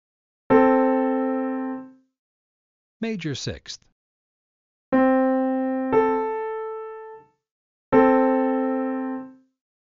9 Major Sixth
Major_Sixth_Example.mp3